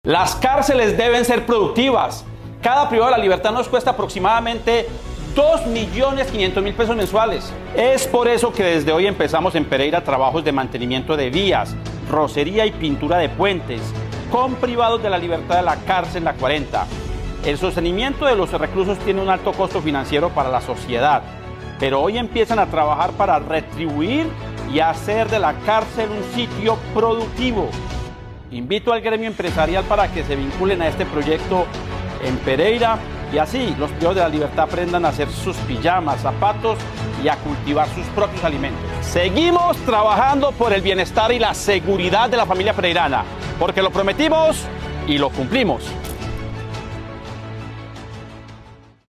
Esto manifestó sobre el tema el Alcalde de Pereira Mauricio Salazar